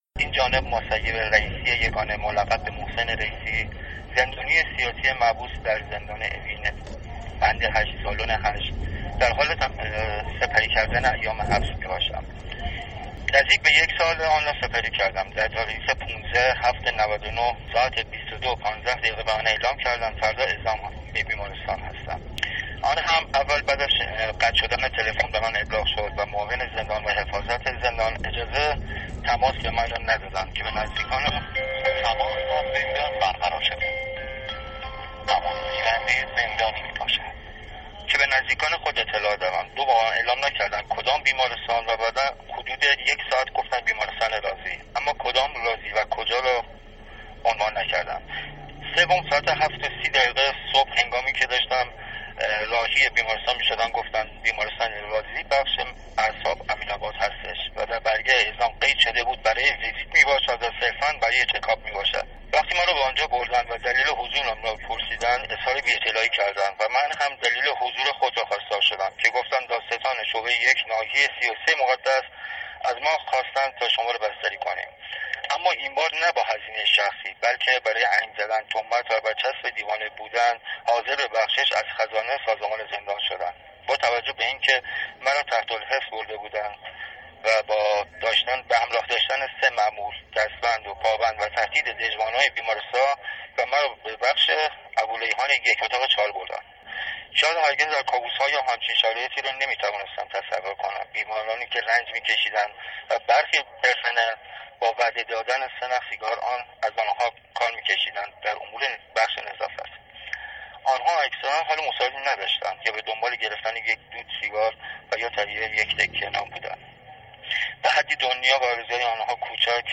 در ششمین قسمت از پادکست «یادآر» زندانیان سیاسی تجربه‌ انتقال اجباری از زندان به بیمارستان‌های روان‌پزشکی و از جمله مهم‌ترین و ای بسا مخوف‌ترینِ آنها برای زندانیان؛ بیمارستان روان‌پزشکی رازی معروف به امین آباد را روایت کرده‌اند.